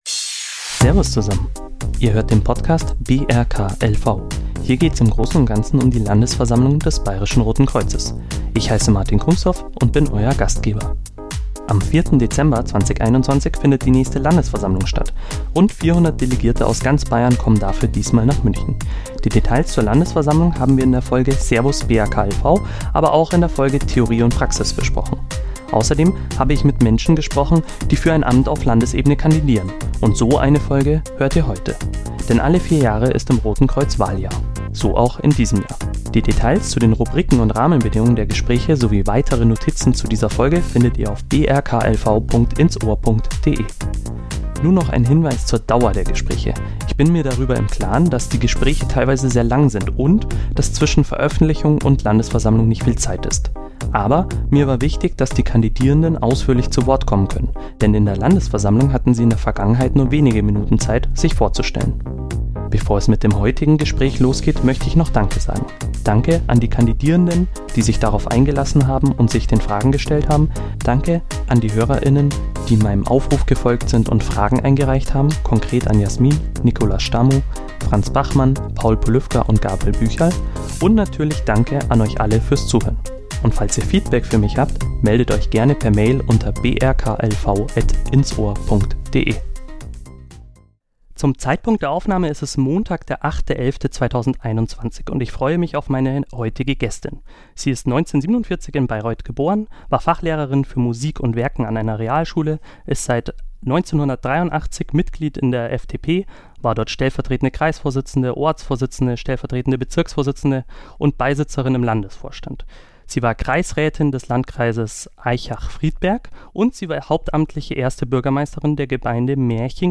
Diese und viele weitere Fragen klären wir in diesem Gespräch. Wir sprechen über das BRK als Arbeitgeber, etwaigen Reformbedarf bei der Verbandsfinanzierung und -struktur, den Stellenwert von Transparenz und Partizipation für ehrenamtliches Engagement, den Stand der Digitalisierung und viele weitere Themen.